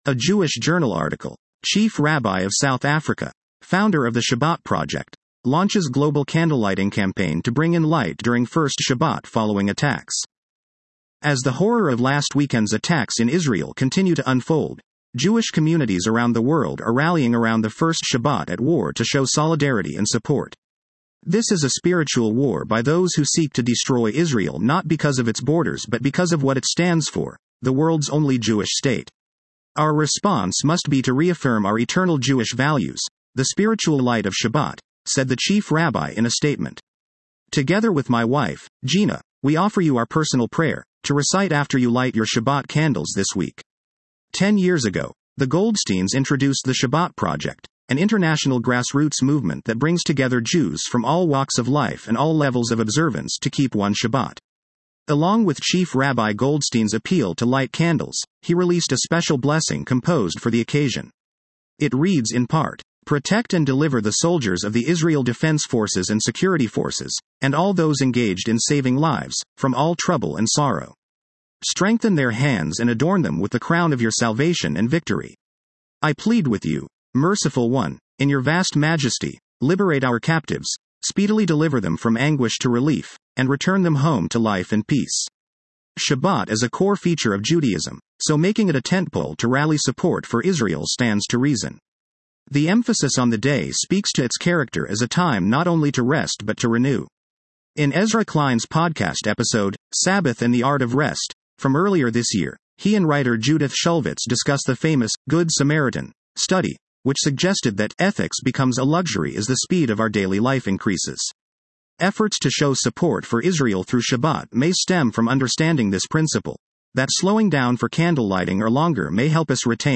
welcoming Shabbat as they light the Shabbat Candles